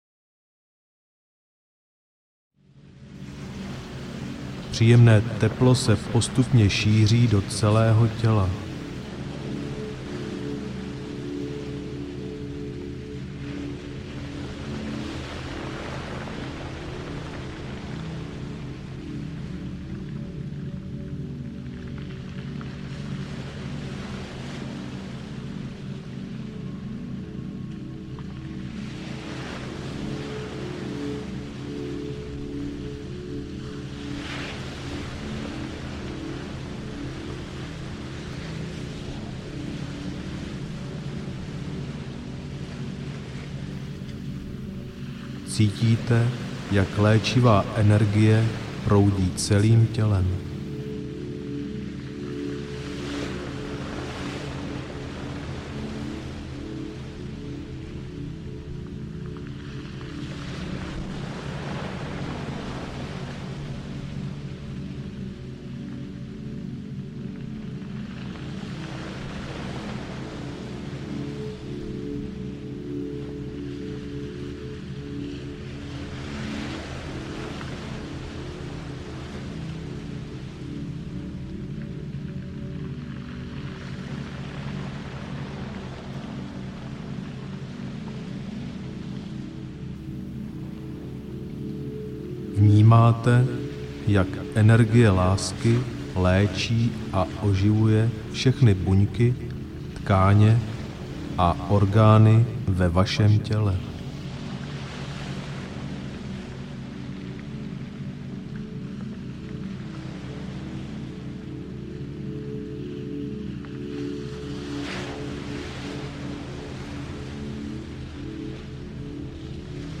Zdraví a vitalita audiokniha
Ukázka z knihy